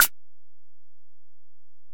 Perc (2).wav